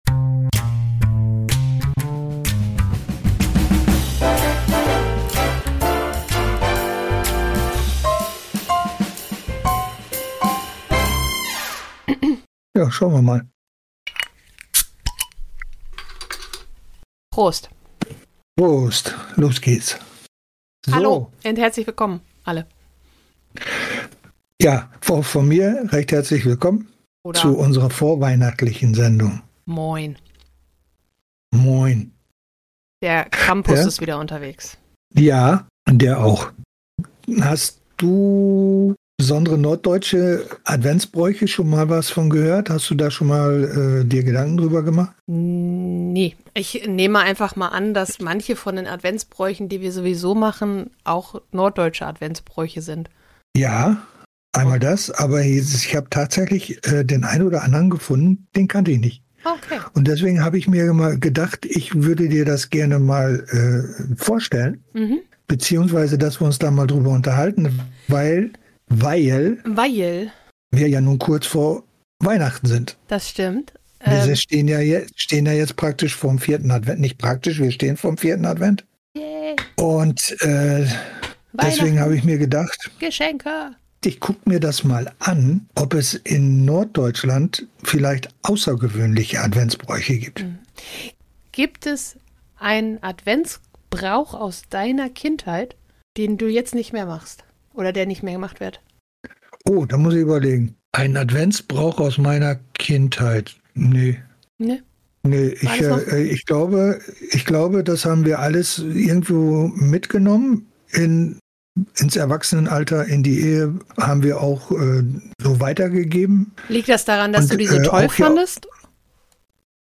Vater, Tochter und ein Bier - Generationengespräche